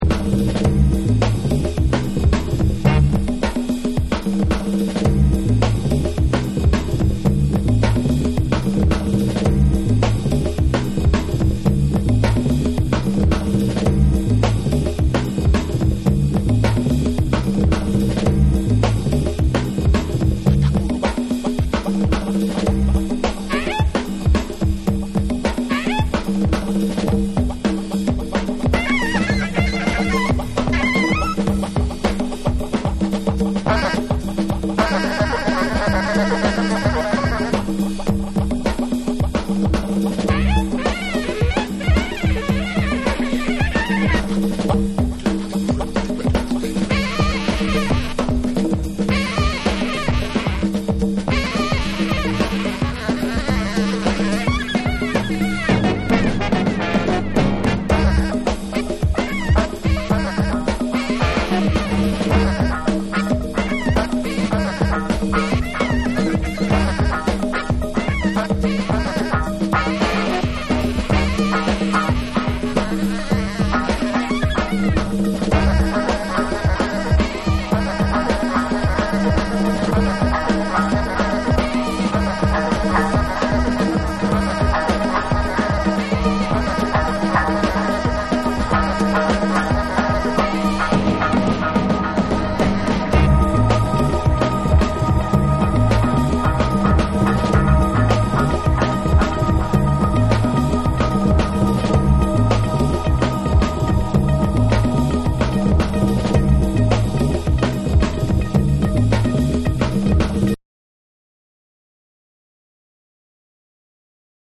ディスコ・ダブともリンクする飛び系効果音やサイケデリックなエフェクトが絡んだコズミック・ブギー
アフロ〜ジャズ〜ファンク、そしてダブの要素が渾然一体となる
絶品人力ダブ・ファンクを全3曲収録。
BREAKBEATS / ORGANIC GROOVE